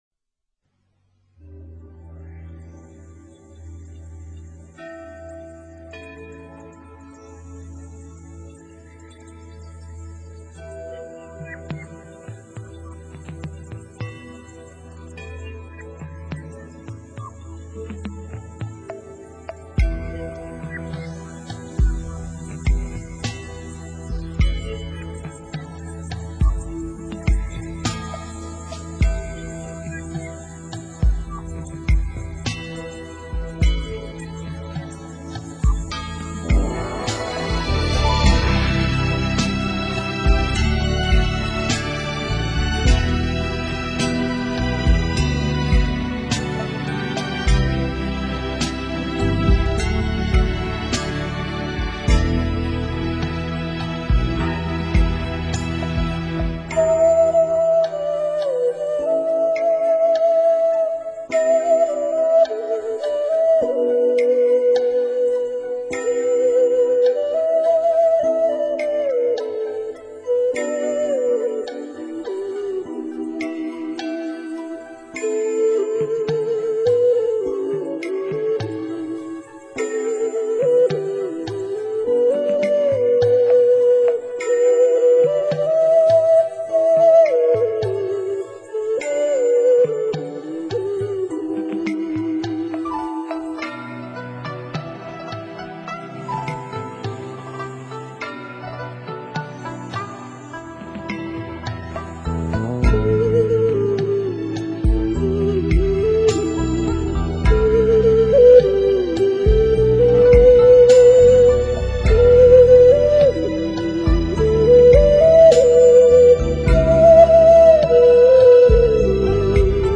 [埙之韵]——《追梦》